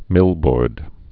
(mĭlbôrd)